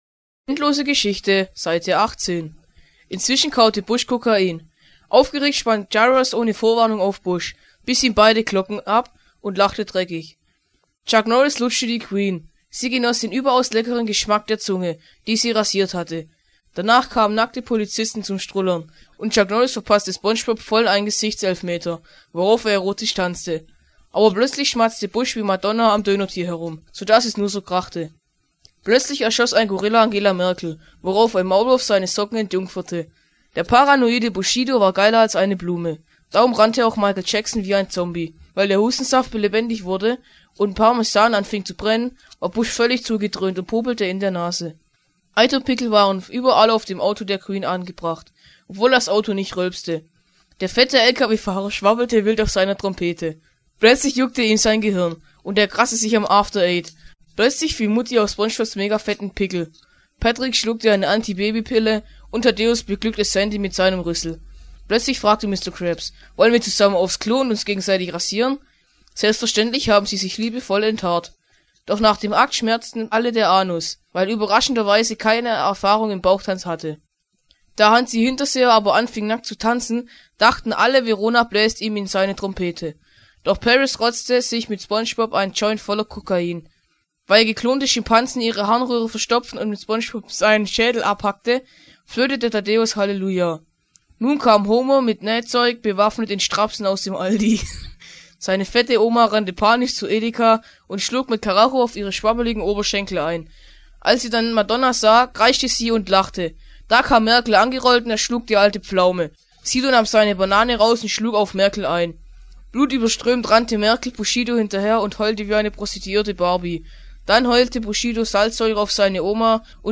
Das Hörbuch zur endlosen Story, Seite 18